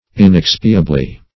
Search Result for " inexpiably" : The Collaborative International Dictionary of English v.0.48: Inexpiably \In*ex"pi*a*bly\, adv. In an inexpiable manner of degree; to a degree that admits of no atonement.